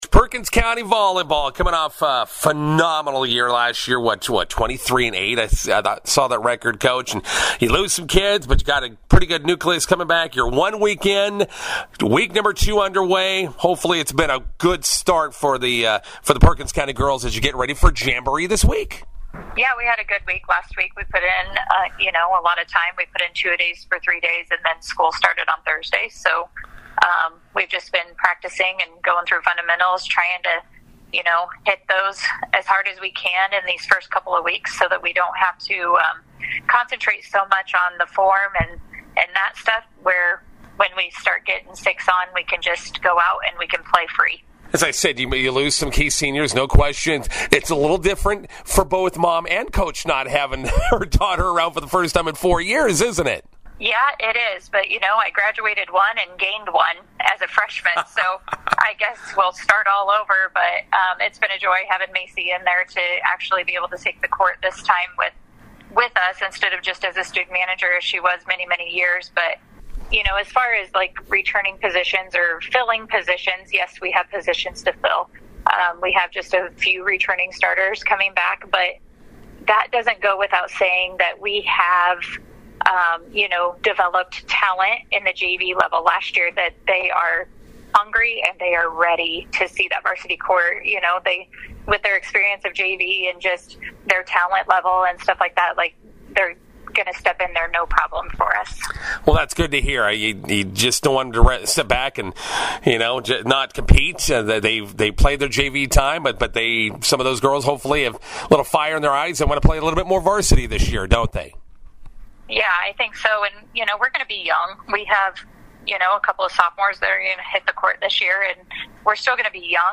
INTERVIEW: Perkins County volleyball opens 2025 fall with jamboree matches tonight vs. McCook and Southwest.